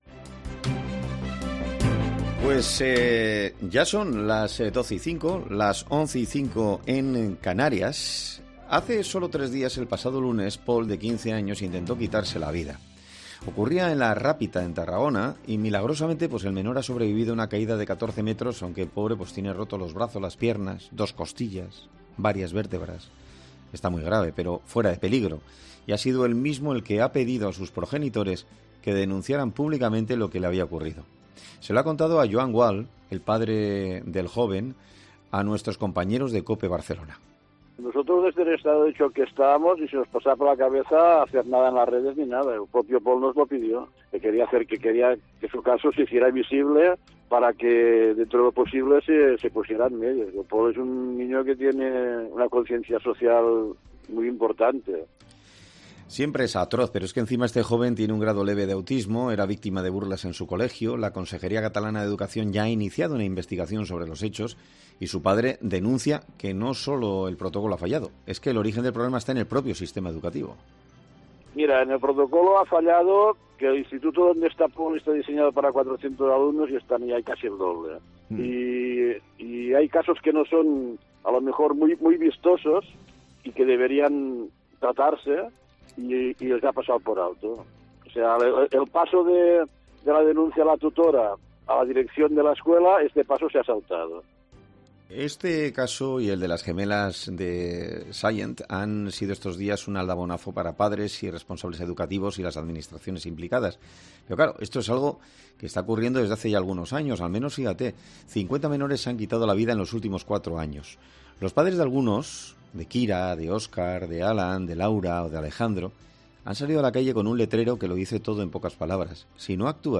En 'Herrera en COPE' hablamos con